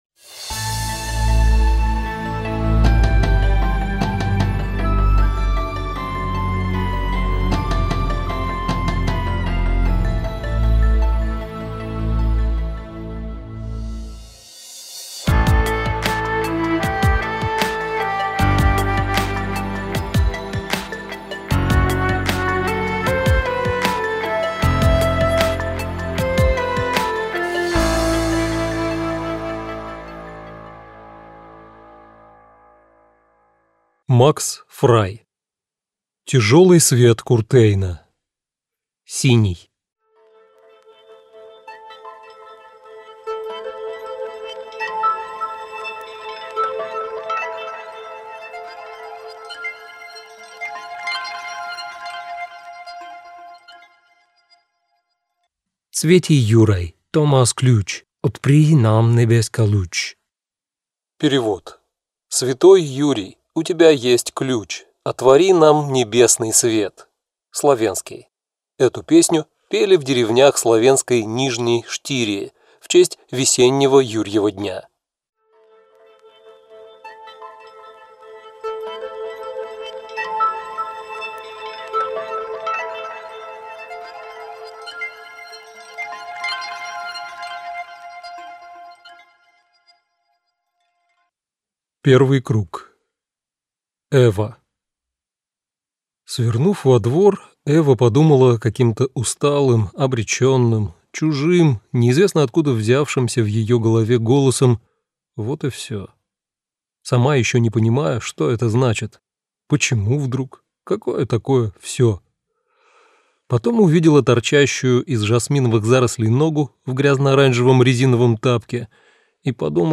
Аудиокнига Тяжелый свет Куртейна. Синий - купить, скачать и слушать онлайн | КнигоПоиск